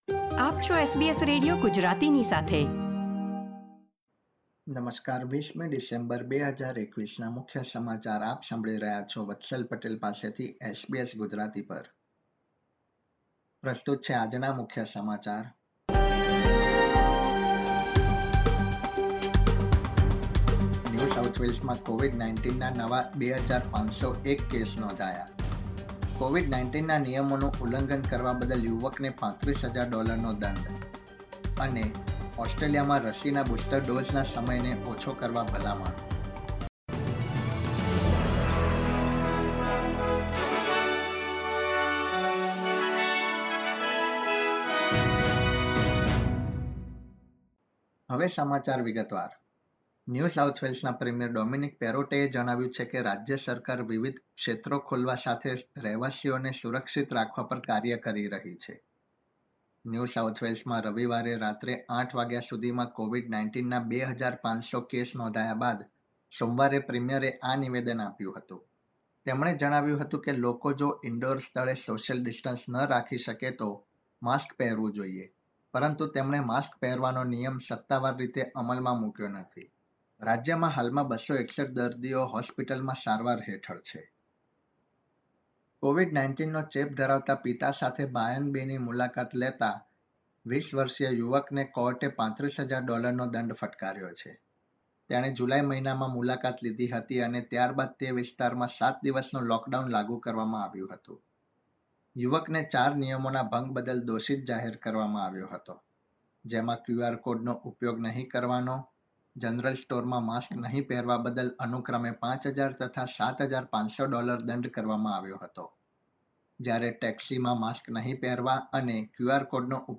SBS Gujarati News Bulletin 20 December 2021